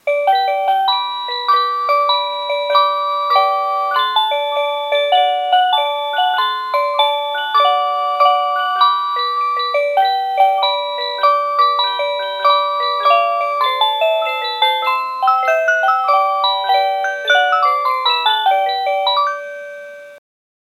08-Cuckoo-Tune.mp3